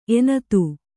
♪ enatu